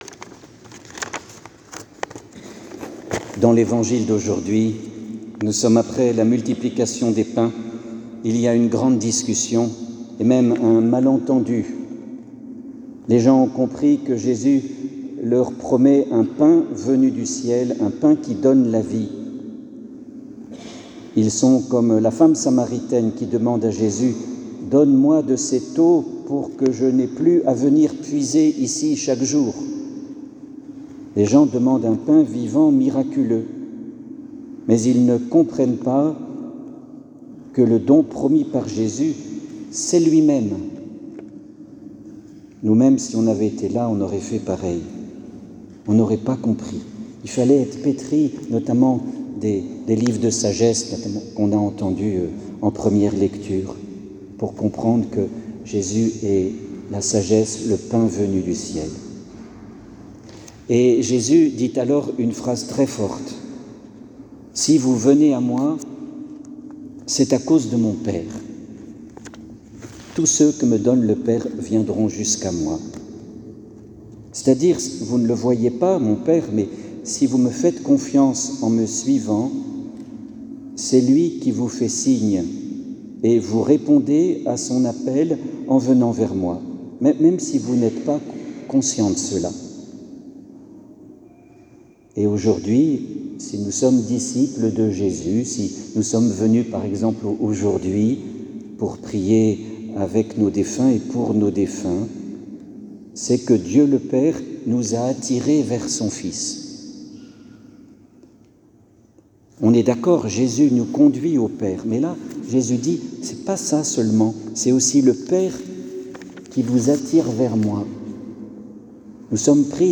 homélie de la commémoration pour les fidèles défunts dimanche 2 novembre (Jn 6, 37-40)